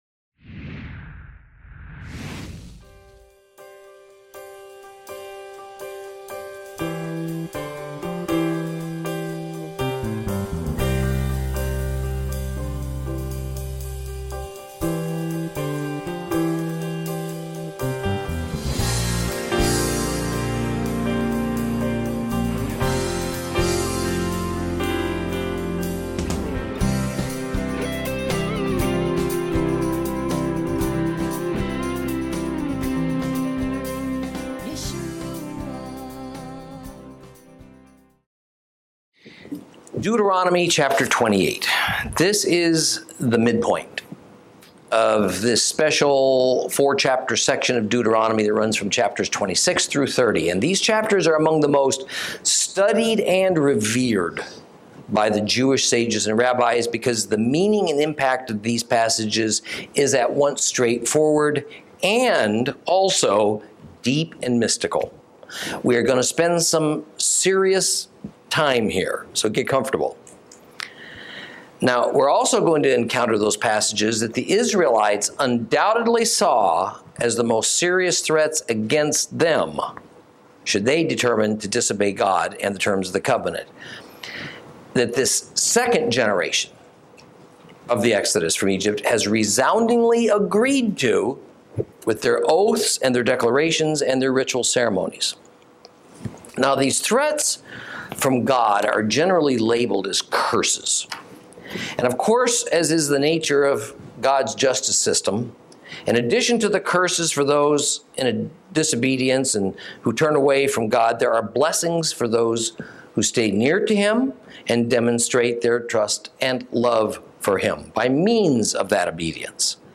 Lesson 38 Ch28 - Torah Class